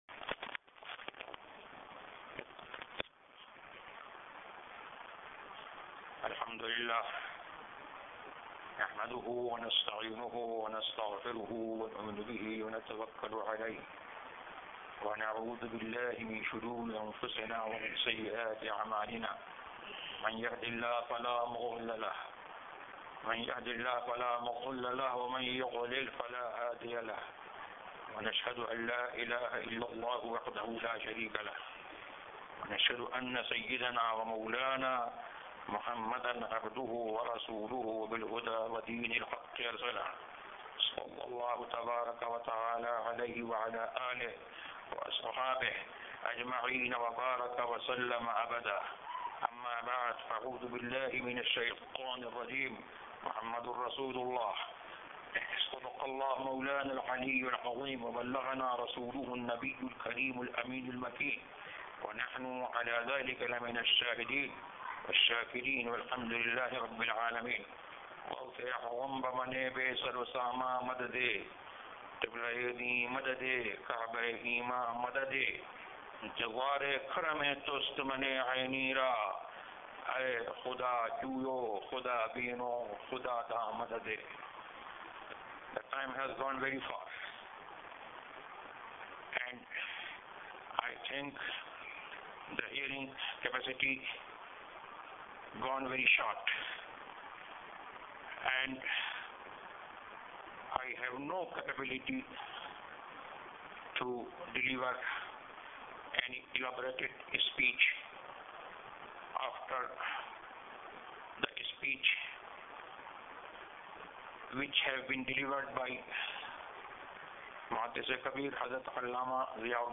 Khutbah at Gaborone
khutbah-at-gaborone.mp3